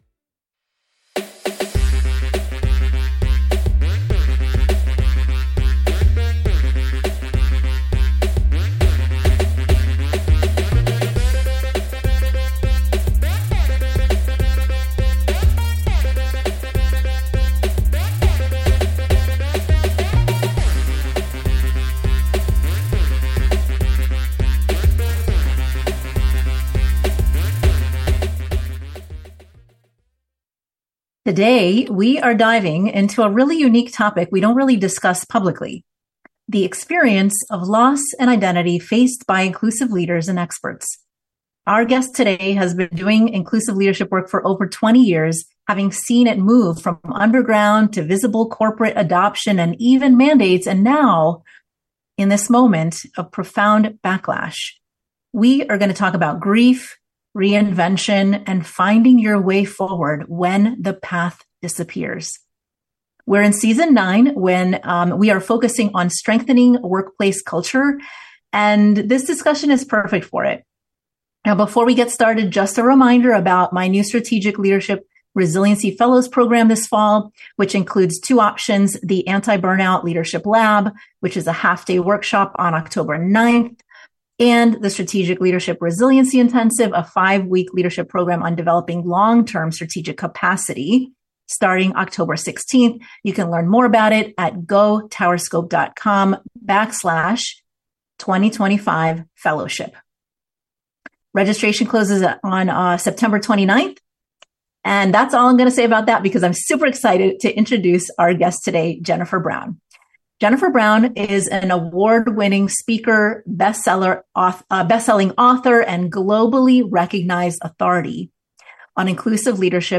Today we're interviewing someone who's witnessed inclusive leadership go from underground movement to corporate mandate to cultural battleground—and lived to tell the story of each transformation.